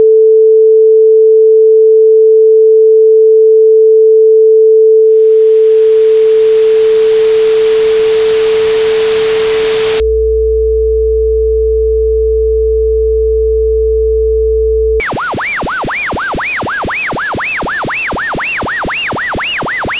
This is what 20 years of hydrolysis sounds like, compressed into 20 seconds.
0:00 - 0:05: The reference signal (440Hz). The lattice is intact.
0:05 - 0:10: The onset of hydrolysis. The binder begins to absorb moisture. The noise floor rises not because of external interference, but because the medium itself is becoming rougher on a microscopic scale.
0:10 - 0:15: The “Bake.” High-frequency loss. The oxide is shedding. The sound becomes muffled as the head gap clogs with the debris of the recording itself.
0:15 - 0:20: Structural failure. The polyester base warps. Azimuth drift introduces phase cancellation. The 48Hz hum isn’t the recording; it’s the vibration of the machine struggling to pull the deformed tape across the path.